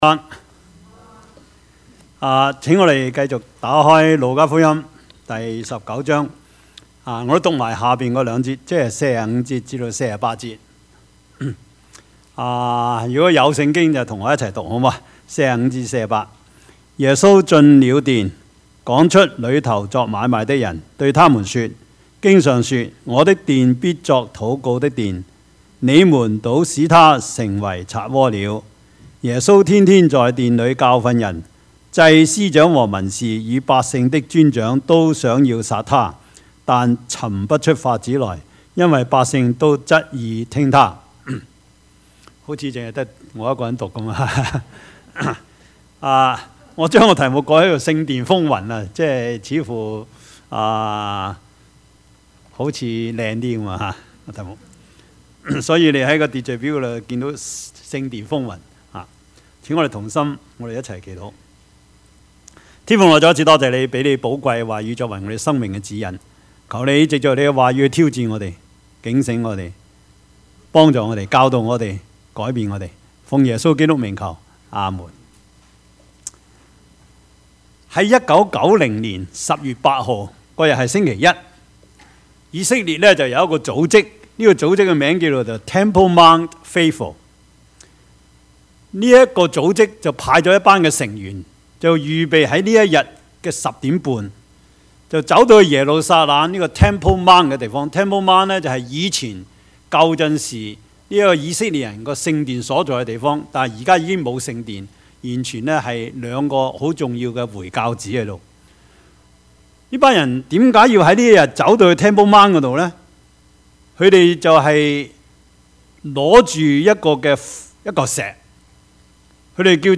Service Type: 主日崇拜
Topics: 主日證道 « 耶穌的眼淚 浮生若夢, 為歡幾何?